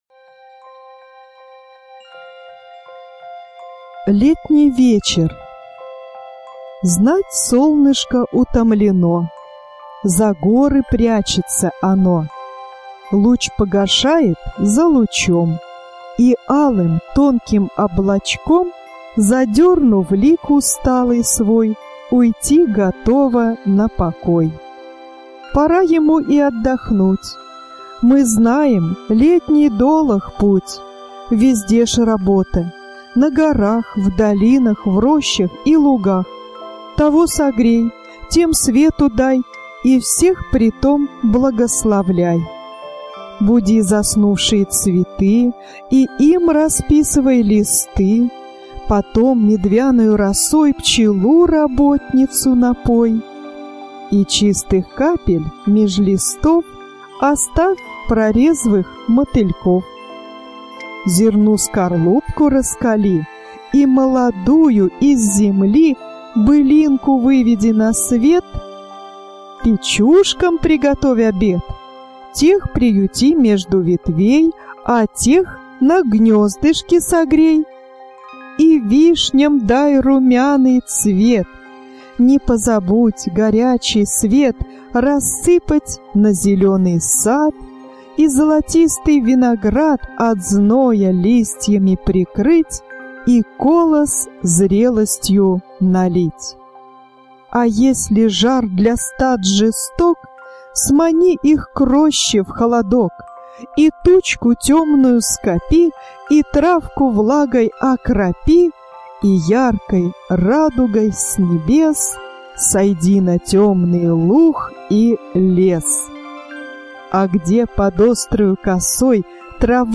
На данной странице вы можете слушать онлайн бесплатно и скачать аудиокнигу "Летний вечер" писателя Василий Жуковский.